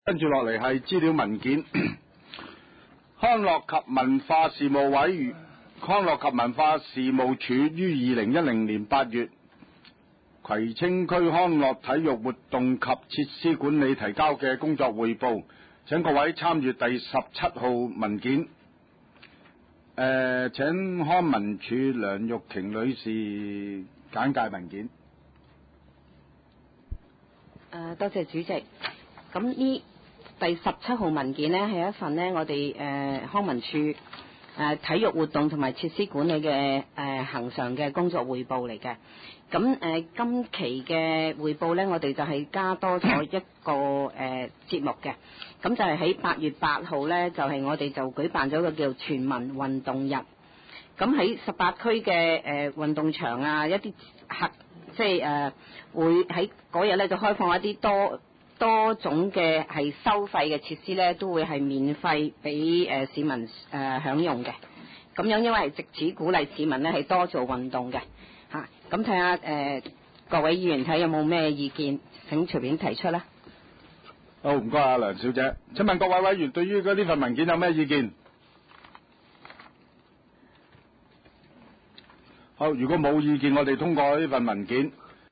葵青民政事務處會議室